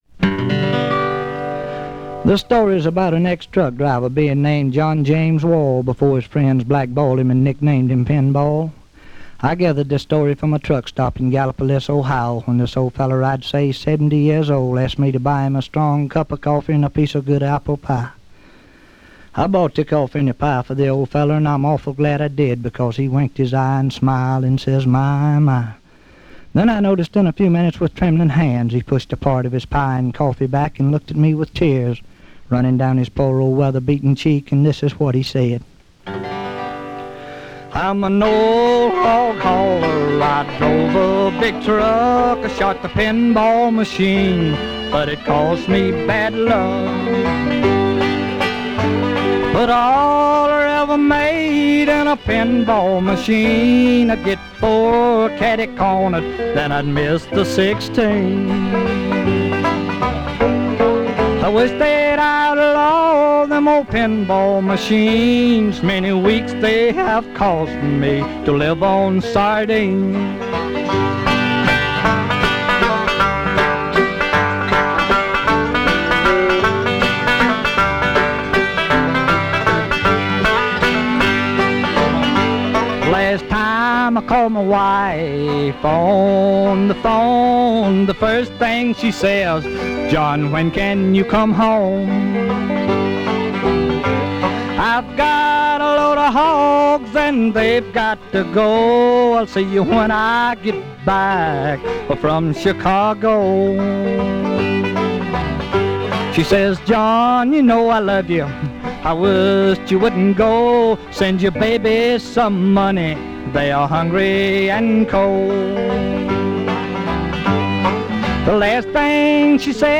who turns out to be a tragic figure in Bluegrass history.